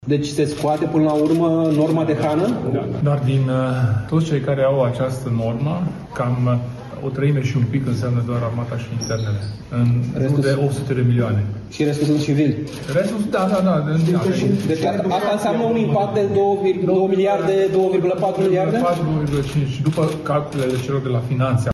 Luni, după ședința Coaliției de Guvernare — ținută la Palatul Victoria — președintele UDMR, Kelemen Hunor, a spus că propunerea privind eliminarea normei de hrană ar fi fost discutată la propunerea Ministerului de Finanțe: